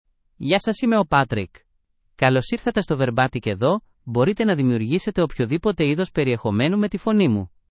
MaleGreek (Greece)
PatrickMale Greek AI voice
Patrick is a male AI voice for Greek (Greece).
Voice sample
Listen to Patrick's male Greek voice.
Male